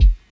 Boom-Bap Kick II.wav